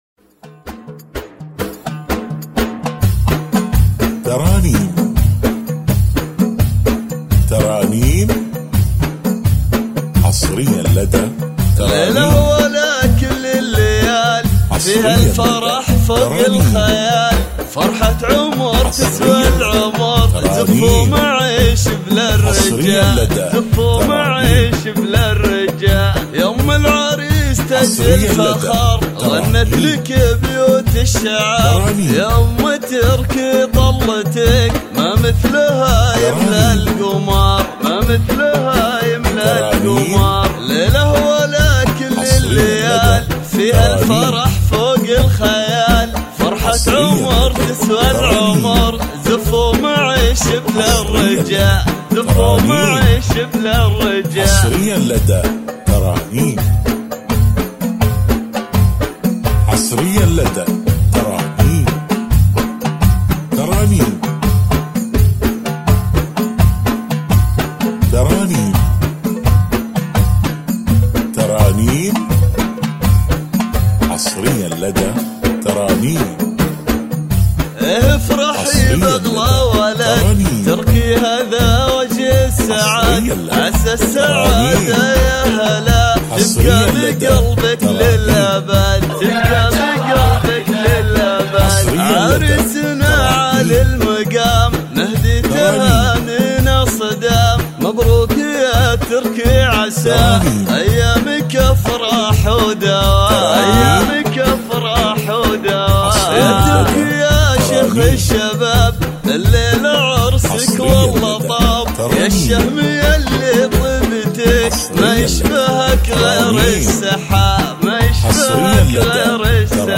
عروس بدون موسيقى